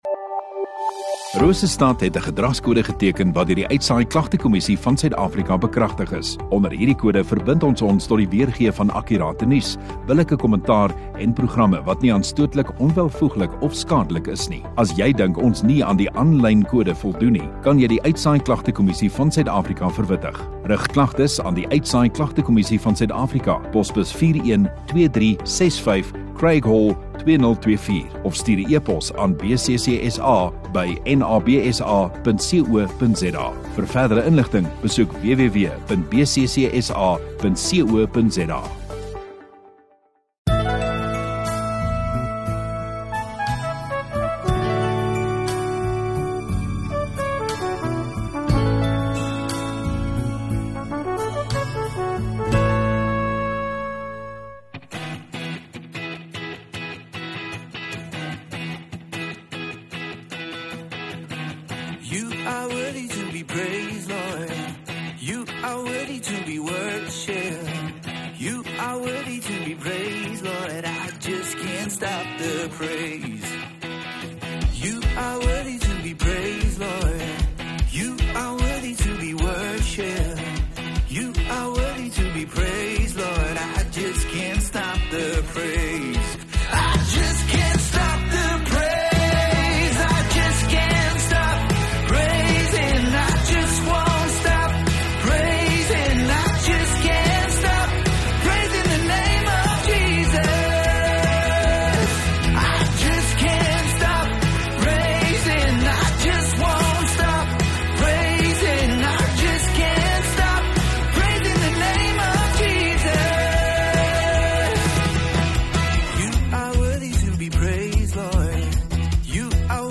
18 Dec Woensdag Oggenddiens